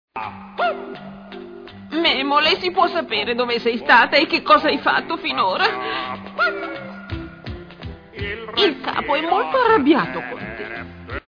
dal film d'animazione "Memole dolce Memole - Il film", in cui doppia Serena.